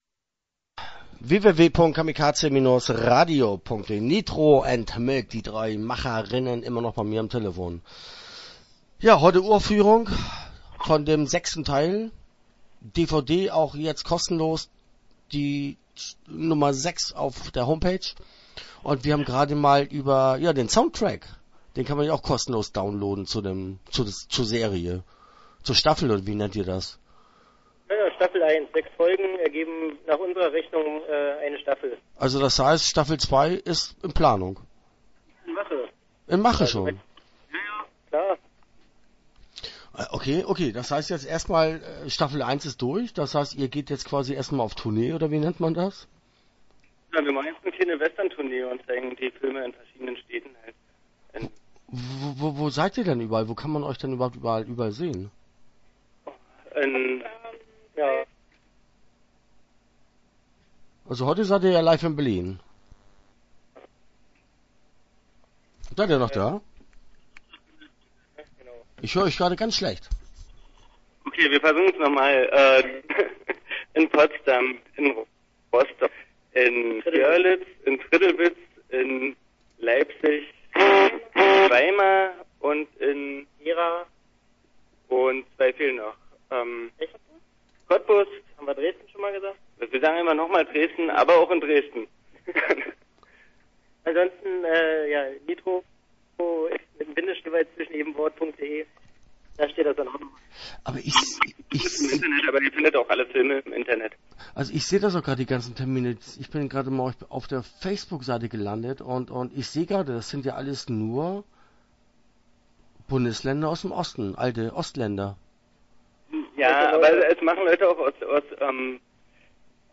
Start » Interviews » Nitro & Milk - Wild West Serie